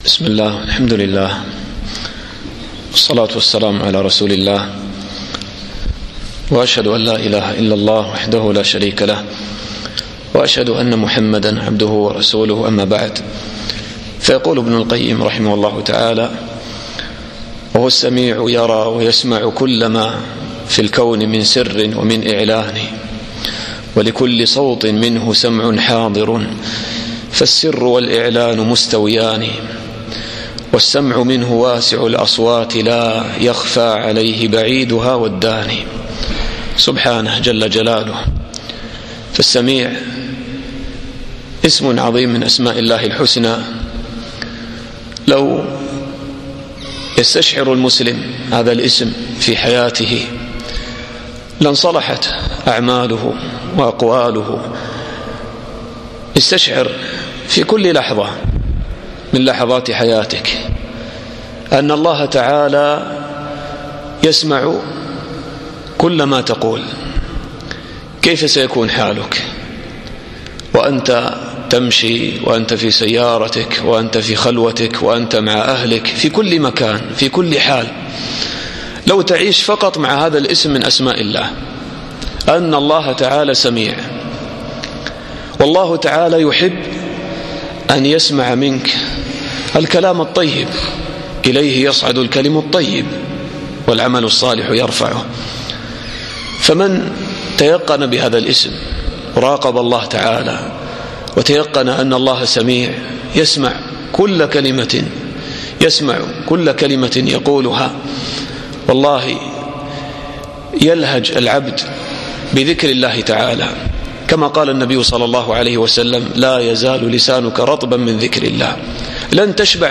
الدرس الثامن